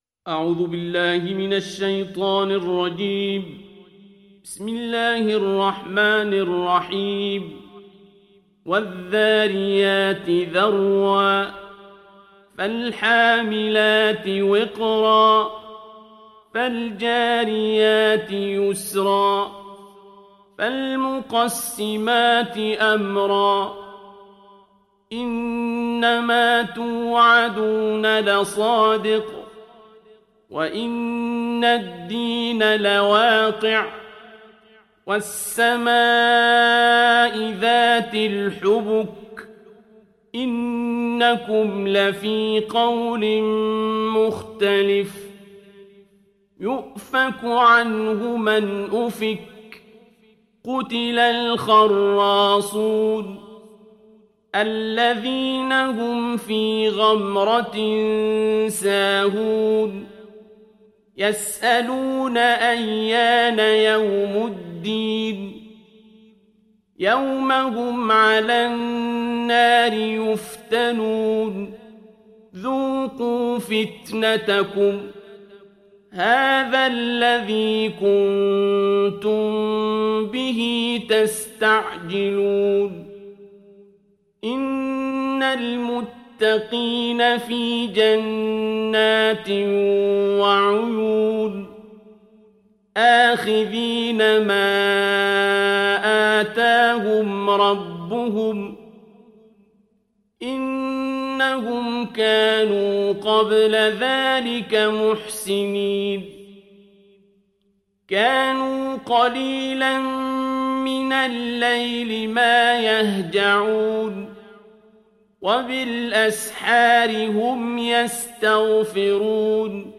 ডাউনলোড সূরা আয-যারিয়াত Abdul Basit Abd Alsamad